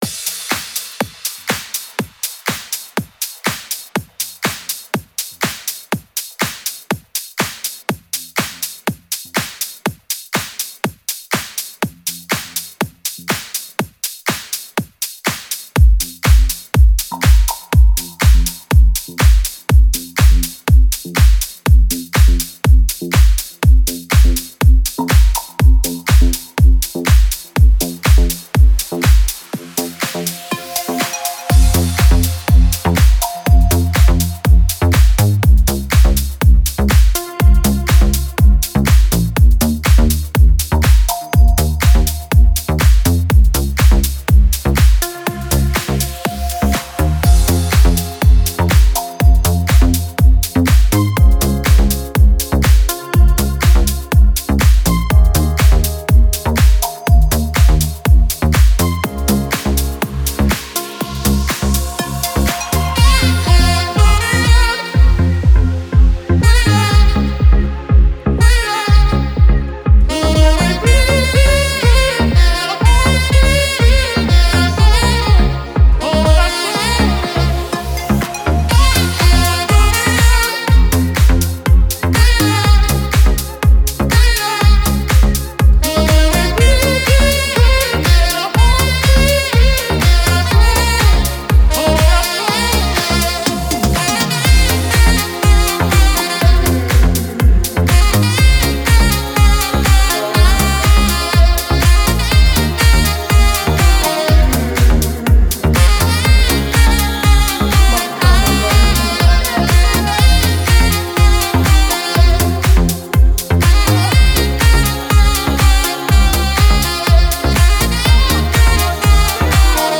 Стиль: Deep House